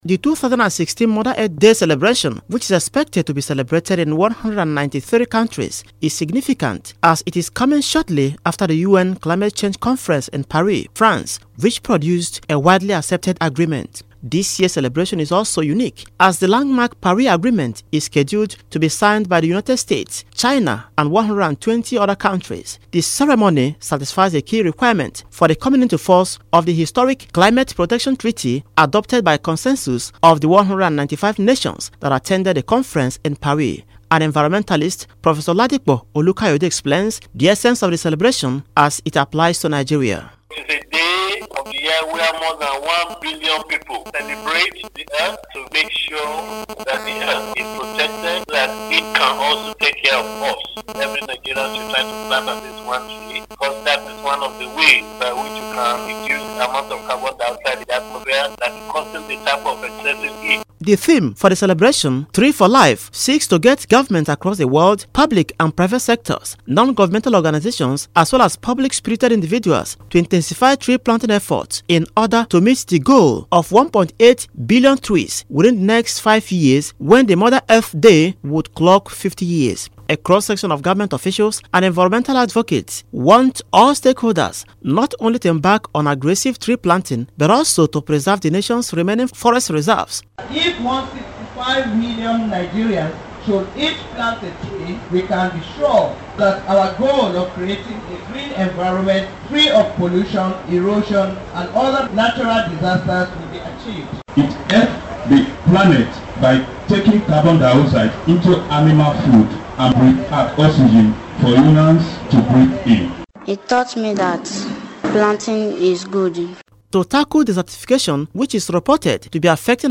Concerned environmental experts made the call in separate interviews with Radio Nigeria to herald this year’s International Mother Earth Day.